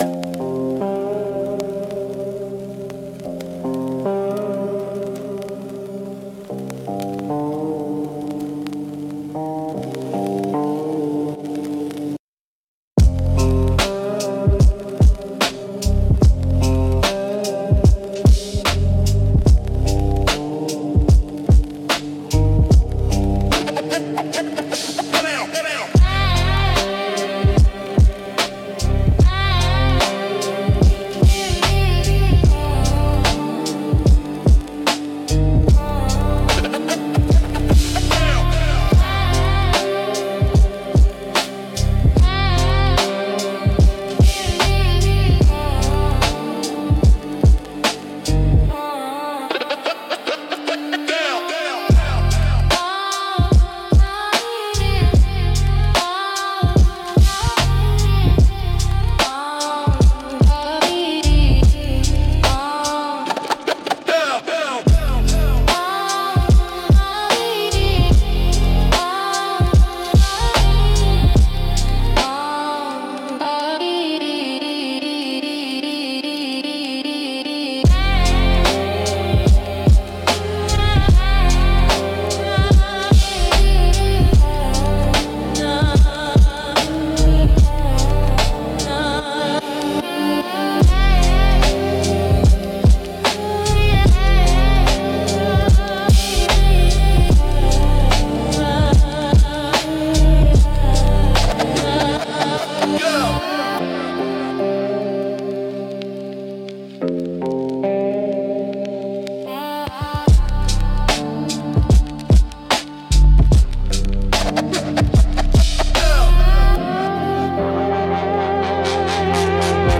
Instrumental - Shadows on the Storefront Glass - Grimnir Radio